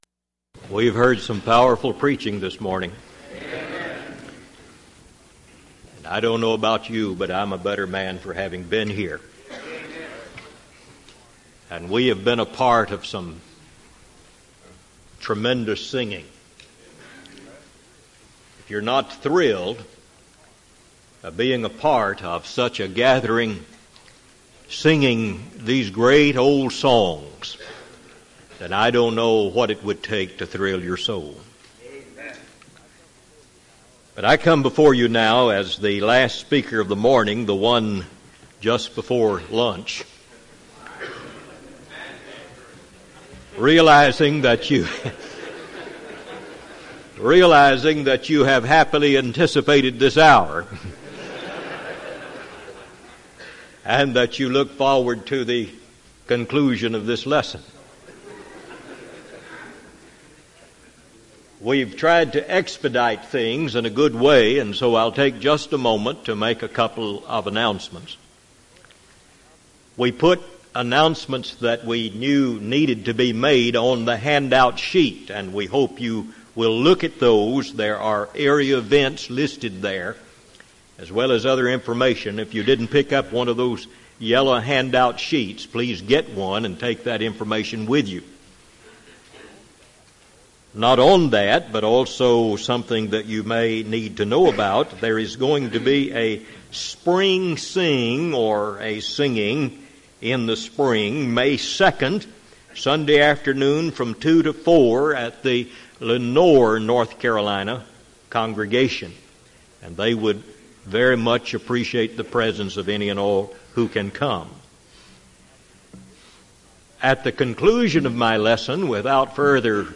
Event: 1999 Carolina Men's Fellowship
lecture